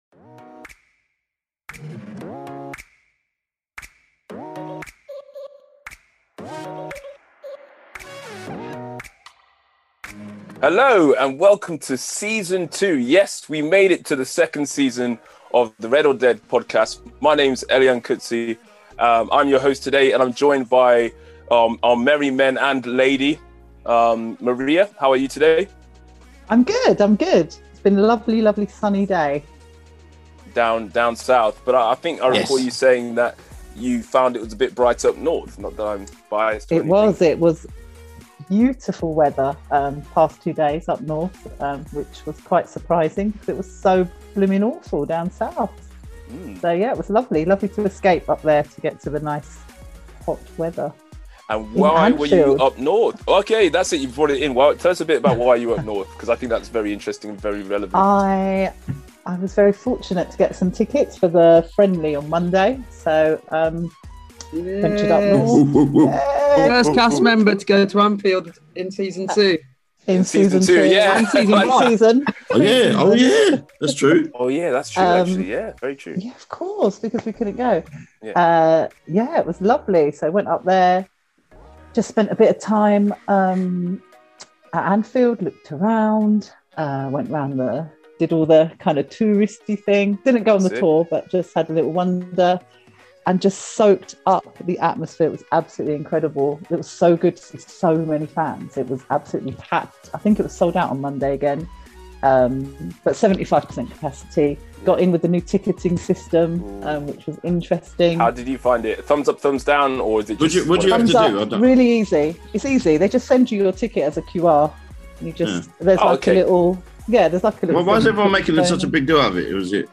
Rested, refreshed, bigger and better with reflections, chat and debate on all things Liverpool FC and beyond.